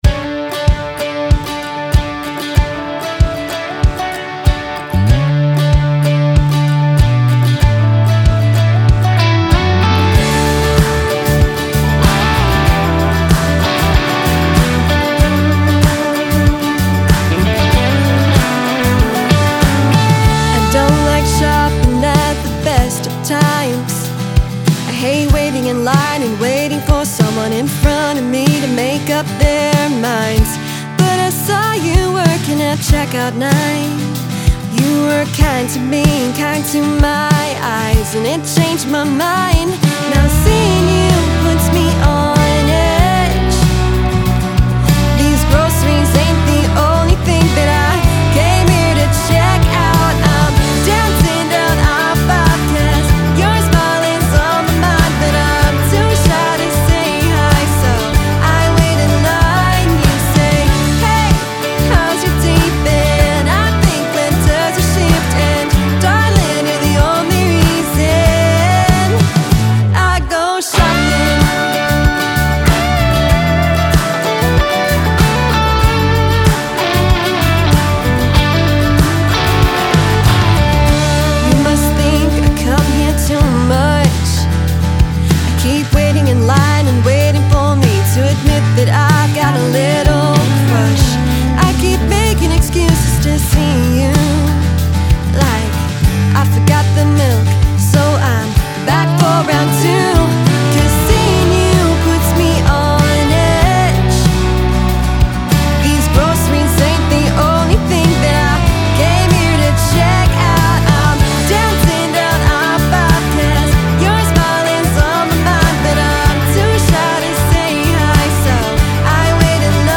singer-songwriter